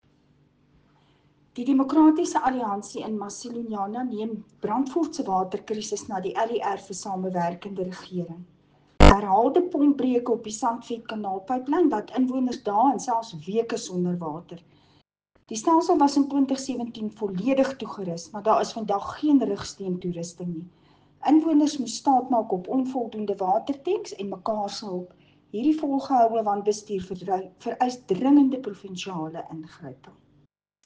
Afrikaans soundbites by Cllr Marieta Visser and